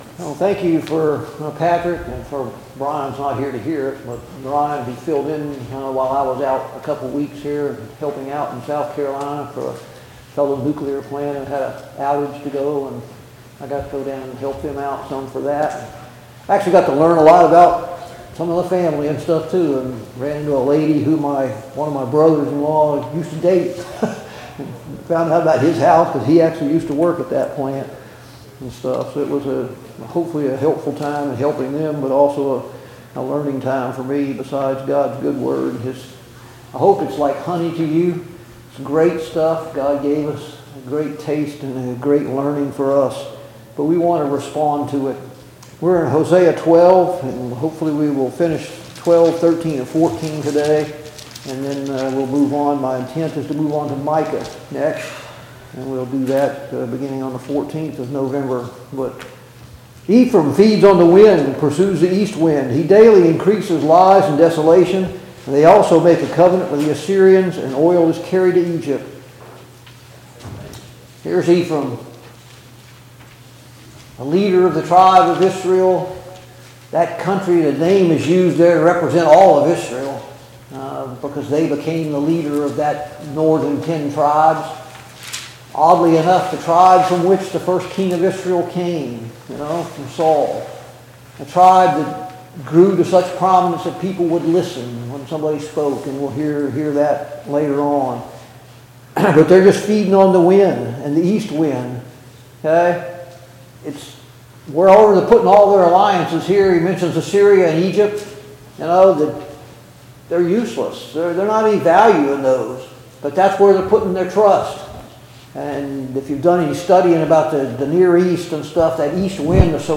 Study on the Minor Prophets Passage: Hosea 11-14 Service Type: Sunday Morning Bible Class « 9.